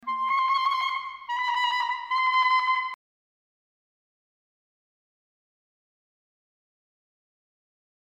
Quarter-Tone Trills and Tremolos
solo clarinet